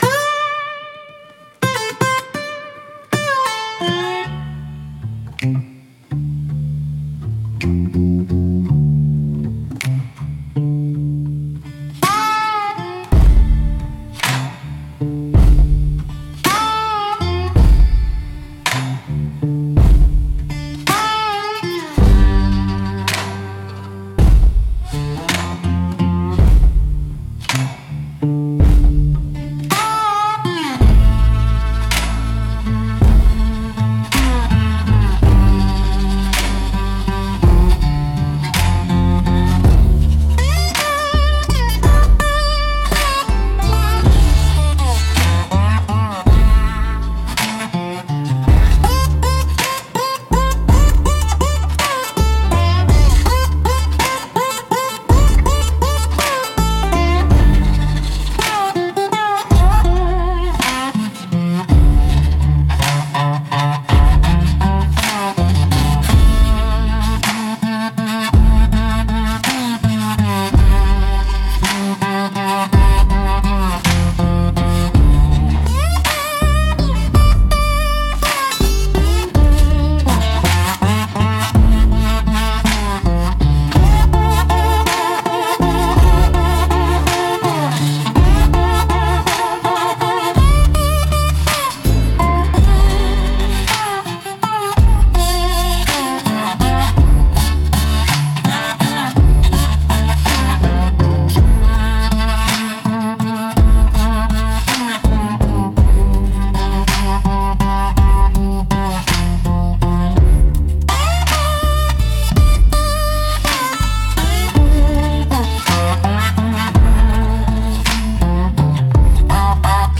Instrumental - Black Smoke 2.52